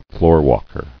[floor·walk·er]